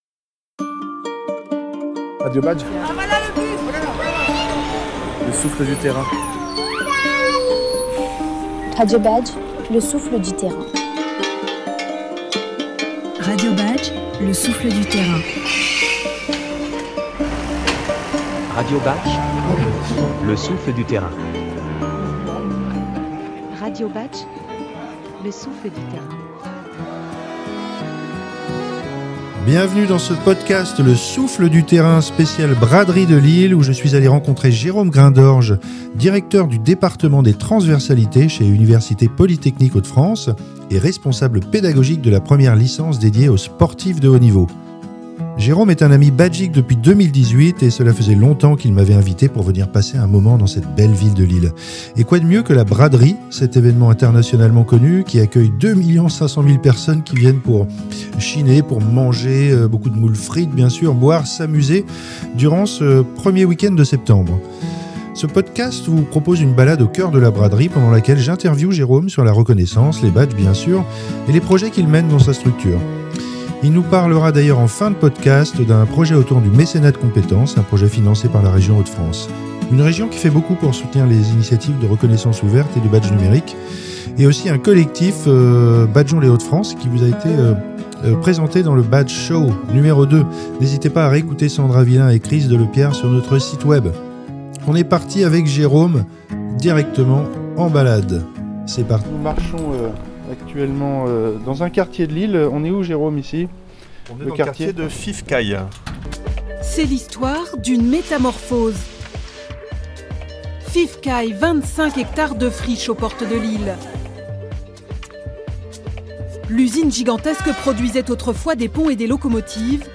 Ce podcast vous propose une balade au coeur de la Braderie